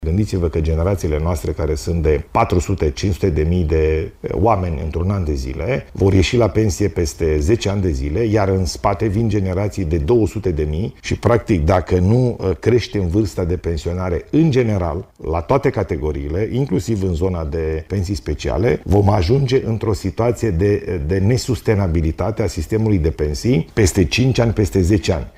Ilie Bolojan a declarat luni seară, într-un interviu la postul public de televiziune, că vârsta de pensionare la toate categoriile trebuie crescută.